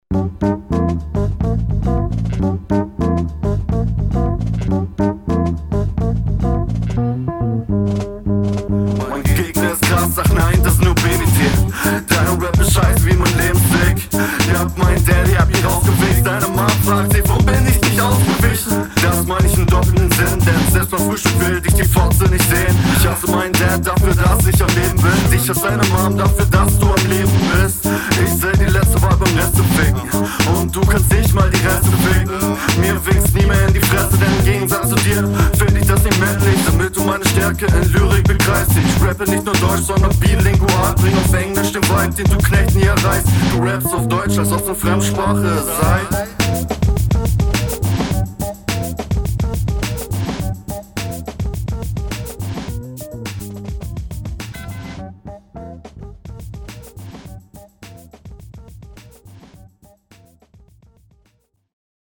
auch nicht so dolle die soundquali und recht standardmäßig gekontert